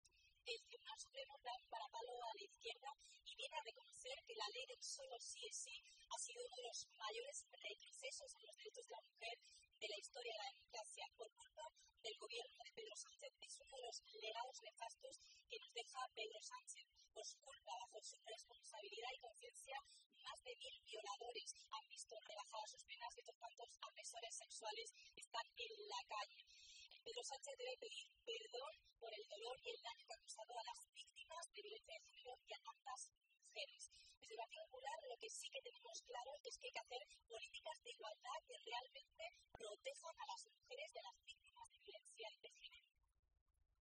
Miriam Guardiola, portavoz del Partido Popular en la Región de Murcia